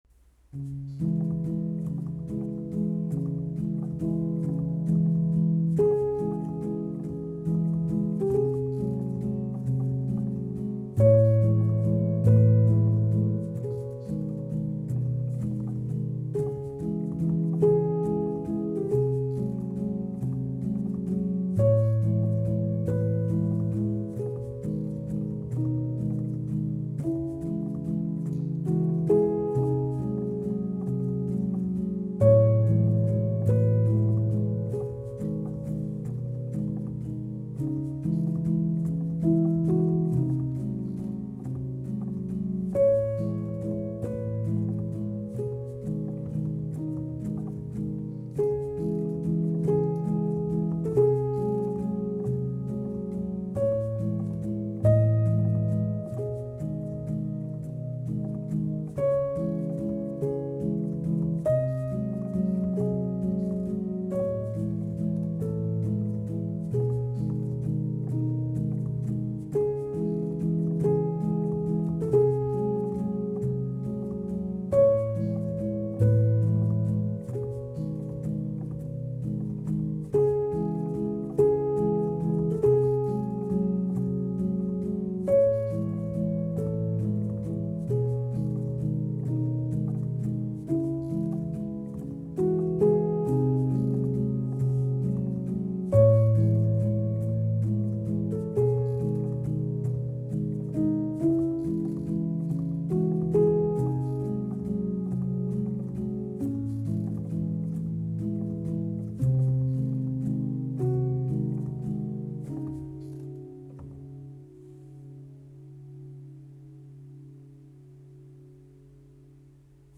a modern classical solo piano single